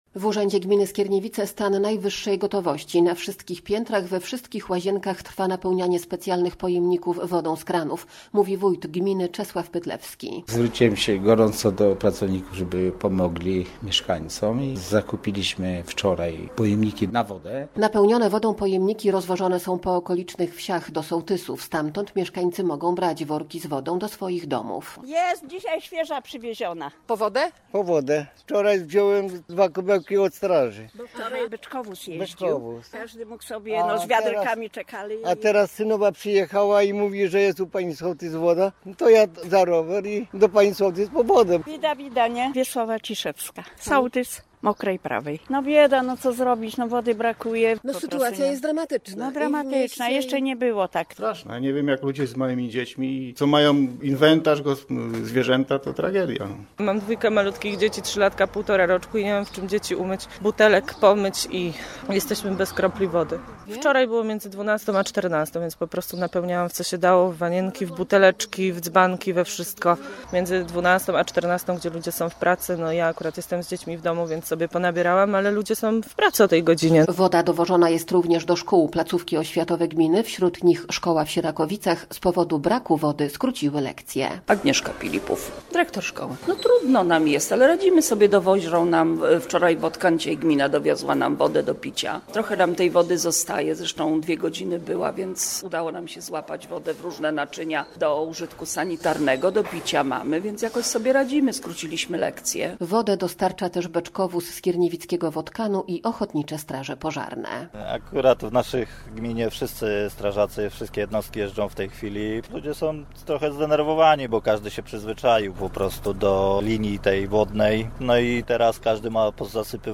Wójt gminy Czesław Pytlewski wyjaśnia, że zwrócił się do pracowników urzędu, aby pomogli mieszkańcom.